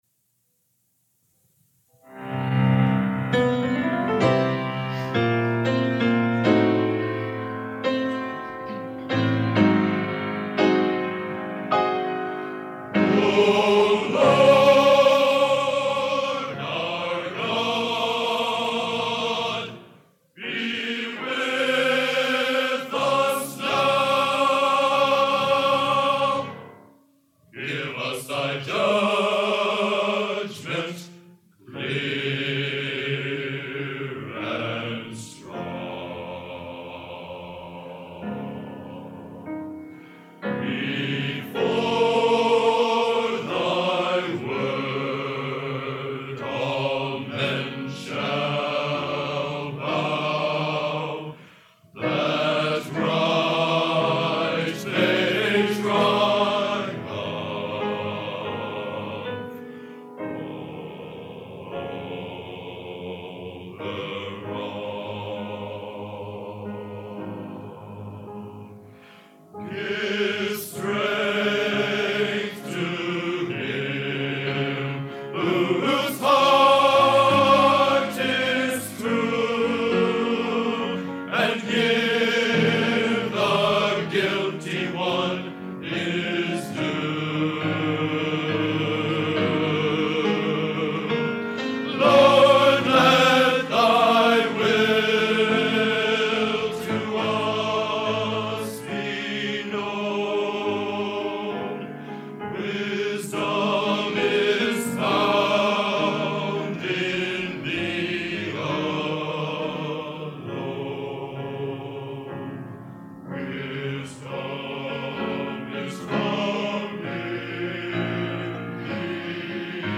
Genre: Classical Opera | Type: End of Season